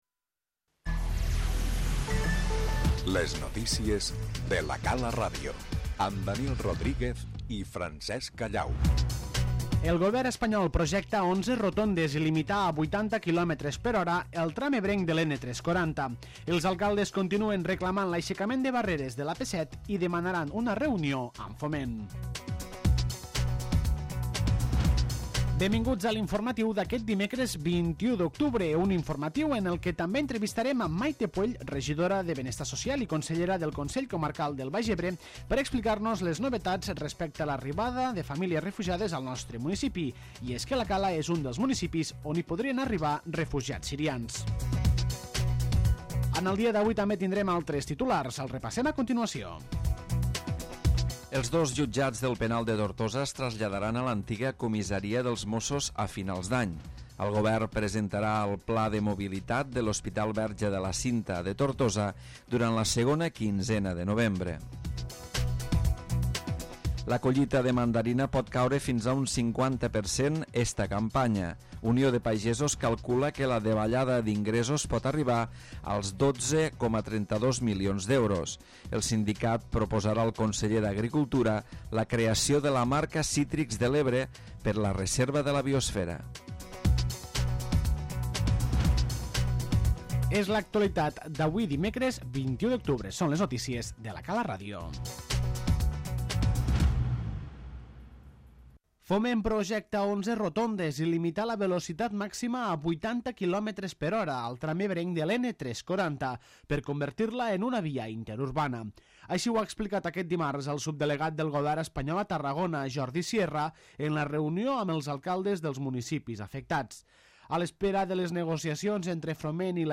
La Cala és un dels municipis on hi podrien arribar refugiats sirians Entrevistem Mayte Puell regidora de benestar social, i consellera del Consell Comarcal del Baix Ebre, per explicar-nos les novetats respecte a l'arribada de famílies refugiades al nostre municipi.